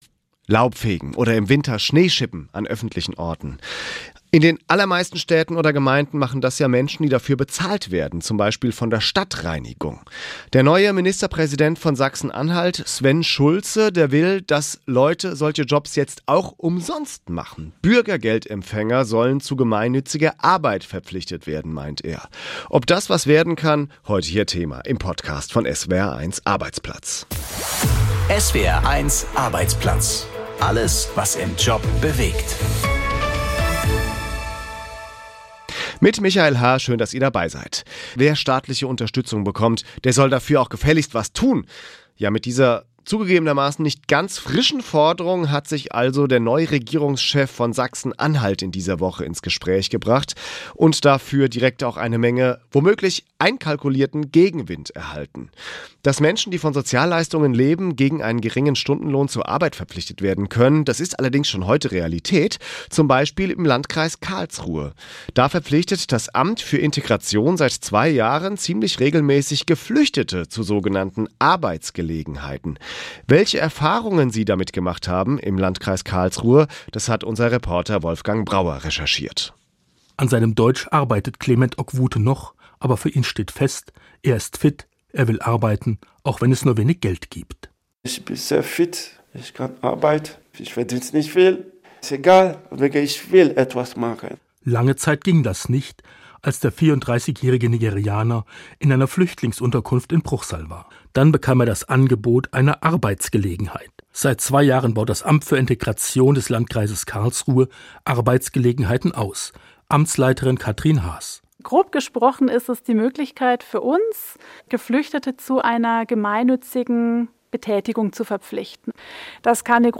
Was bringt eine Arbeitspflicht für bestimmte Menschen? Unser Reporter stellt ein entsprechendes Projekt für Flüchtlinge in Karlsruhe vor ++ Die Debatte um eine Arbeitspflicht für Bürgergeldempfänger geht in die nächste Runde.